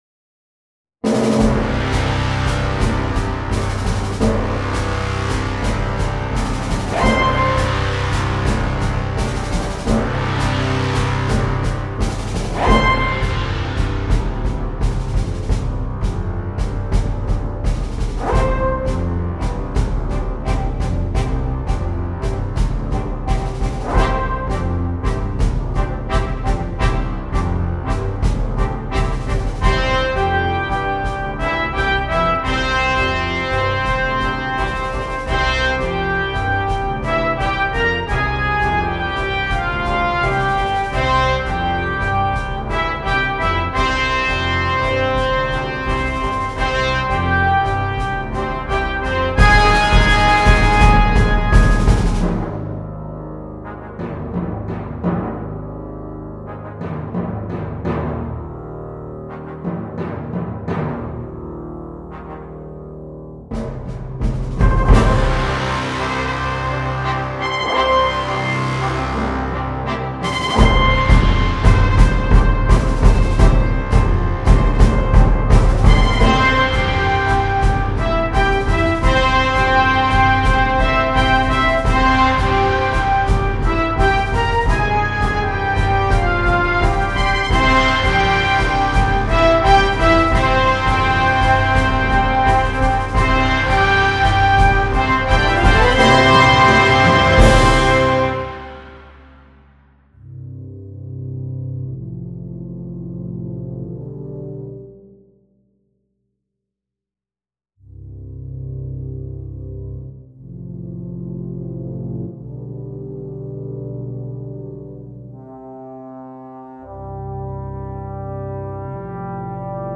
A Suite for Brass Band